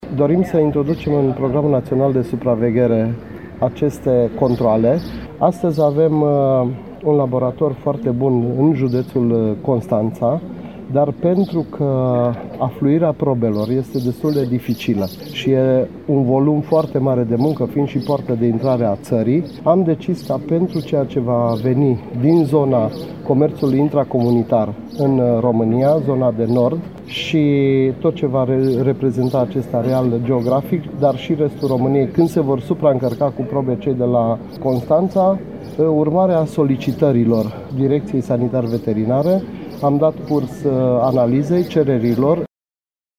O astfel de unitate mai există doar la Constanţa şi este supraaglomerată, a declarat la Arad, şeful Autorităţii Naţionale Sanitar Veterinare şi pentru Siguranţa Alimentelor, Radu Roatiş Cheţan.